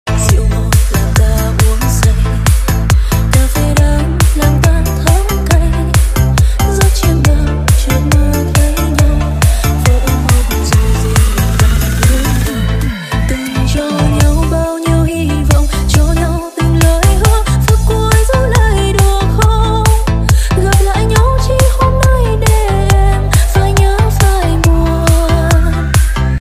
Các chị cổ vũ nhiệt sound effects free download